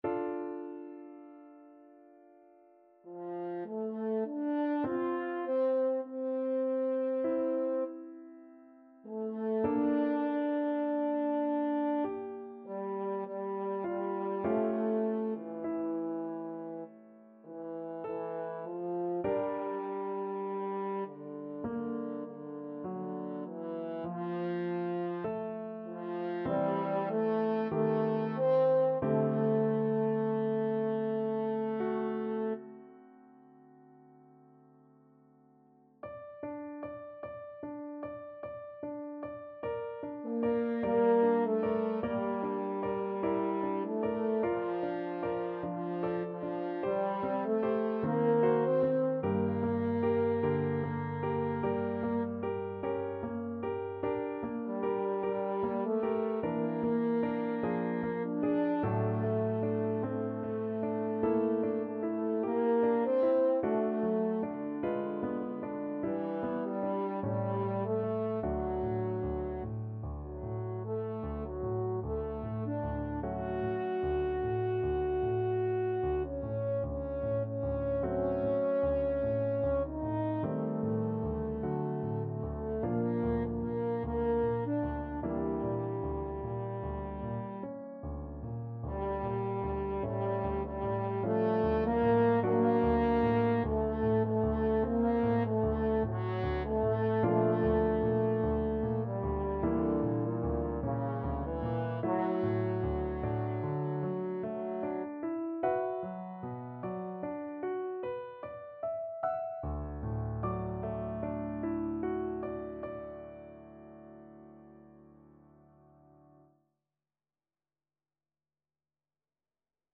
French Horn
D minor (Sounding Pitch) A minor (French Horn in F) (View more D minor Music for French Horn )
4/4 (View more 4/4 Music)
~ = 100 Lento =50
Classical (View more Classical French Horn Music)